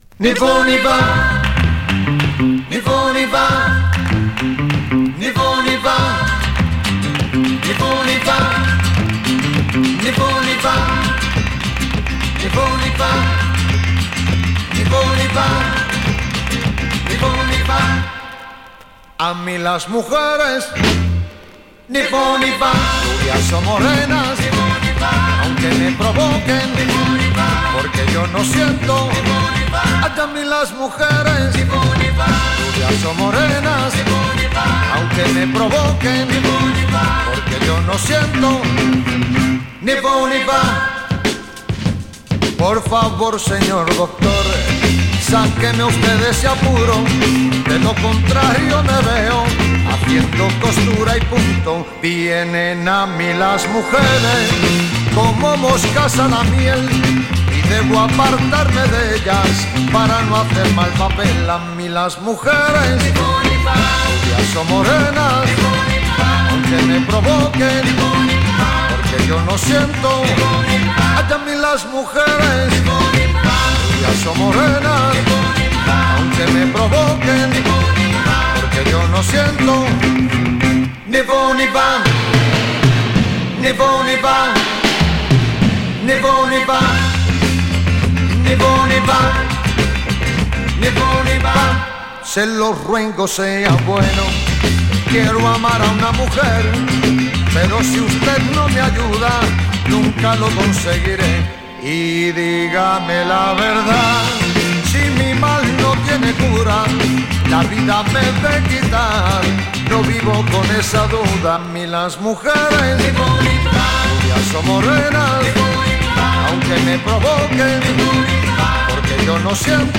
Published June 2, 2011 Garage/Rock Comments